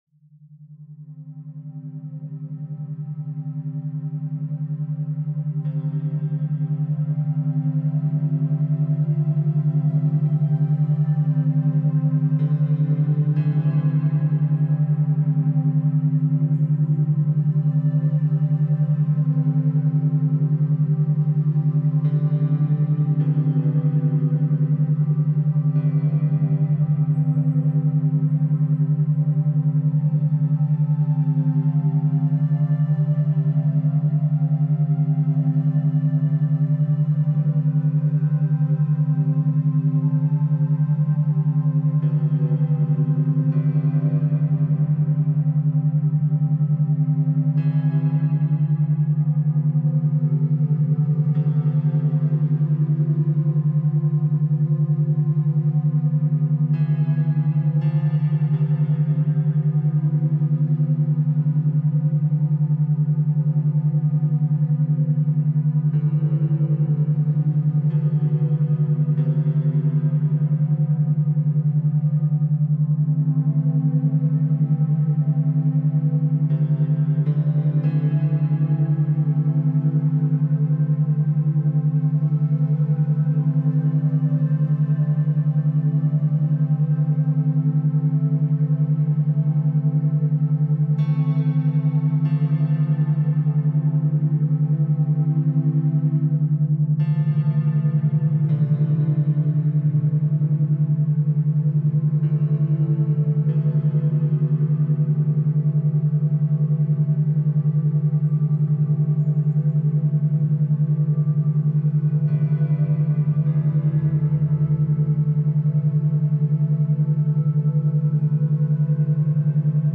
Background Sounds, Programming Soundscapes, Coding Beats